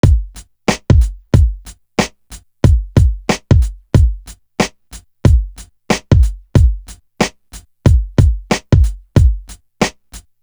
Grand Entry Drum.wav